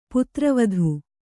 ♪ putra vadhu